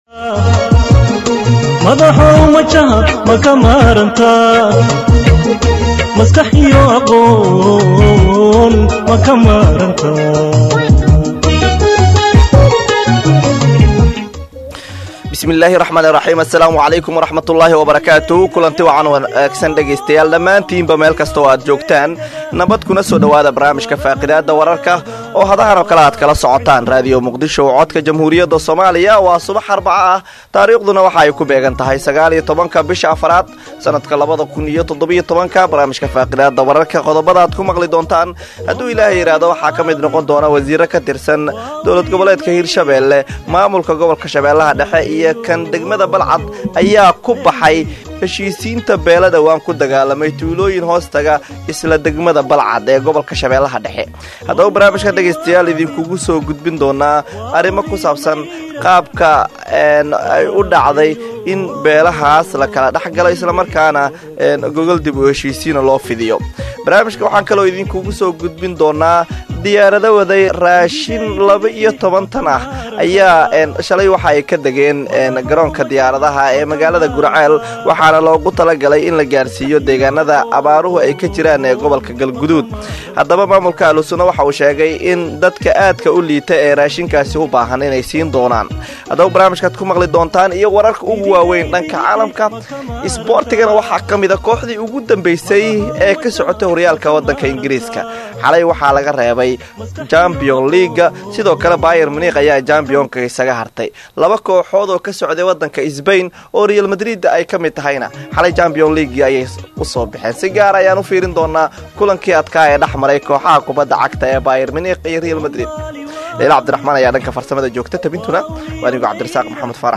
Akhristayaasheena sharafta leh waxaan halkaan idinkugu soo gudbineynaa barnaamijka Faaqidaada oo ka baxa Radio Muqdisho subax waliba marka laga reebo subaxda Jimcaha, waxaana uu xambaarsanyahay macluumaad u badan wareysiyo iyo falaqeyn xagga wararka ka baxa Idaacadda, kuwooda ugu xiisaha badan.